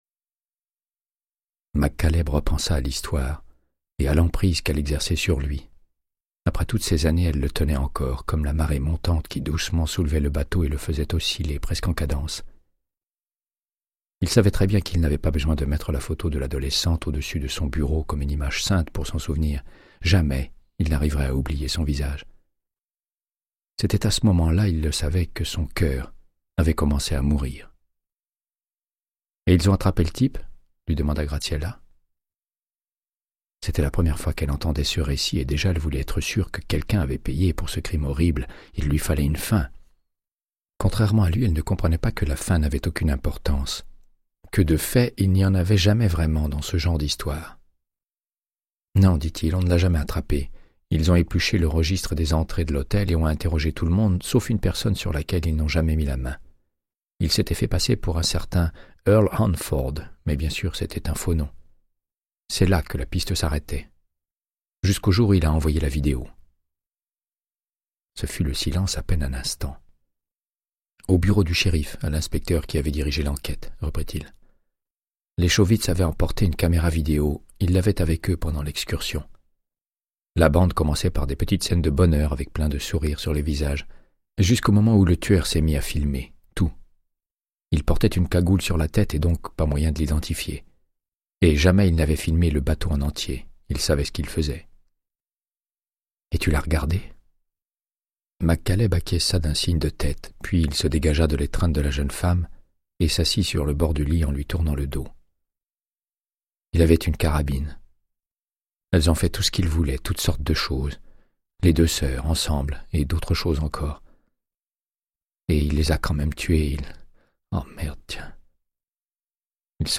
Audiobook = Créance de sang, de Michael Connelly - 94